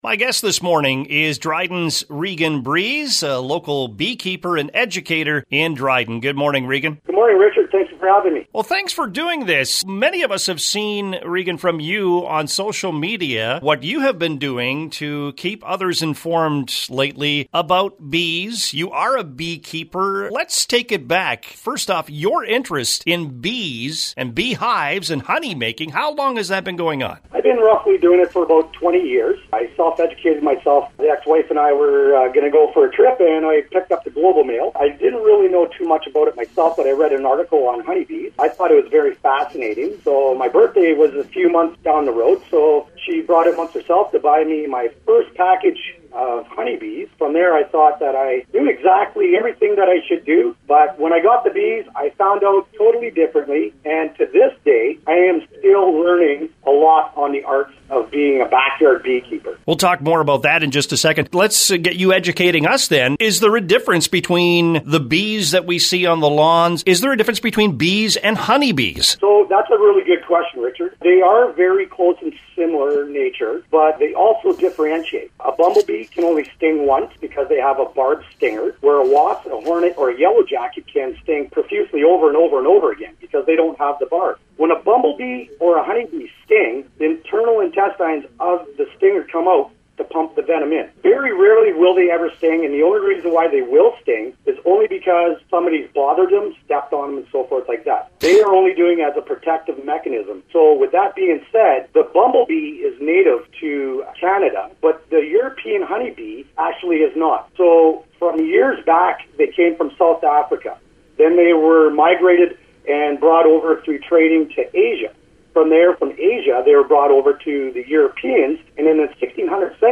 was a guest on the CKDR Morning Show today.
interview